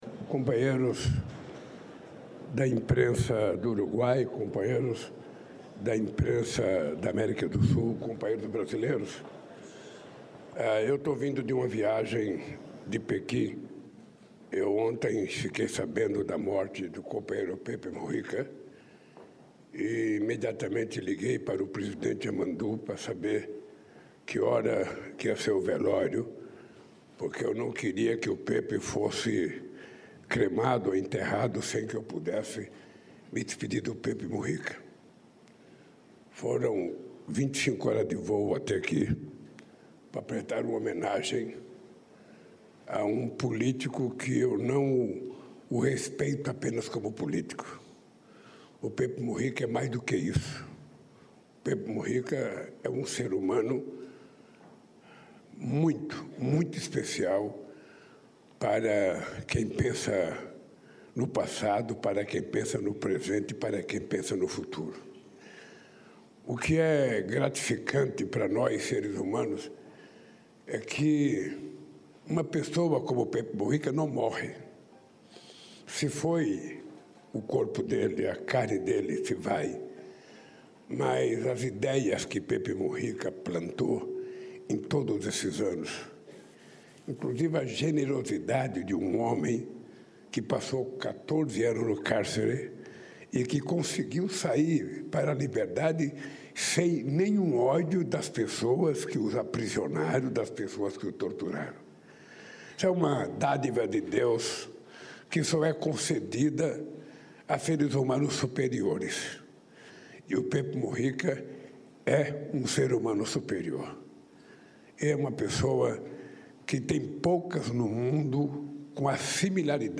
Mensaje del presidente de Brasil, Luiz Inácio “Lula” da Silva
El mandatario brasileño asistió al velatorio del expresidente José Mujica y, antes de retirarse, brindó una declaración.